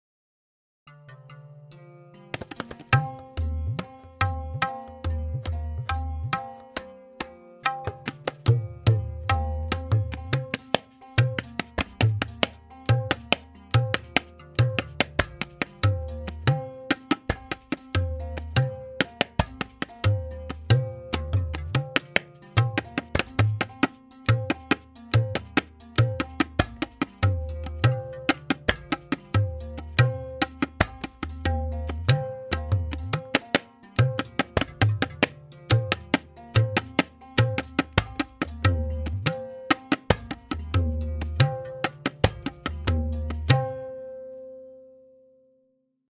Note: The following forms are all played in dugun (2:1).
Farmaishi Chakradar with Theka and Lahra
M8.5-Farmaishi-Theka-Lehra.mp3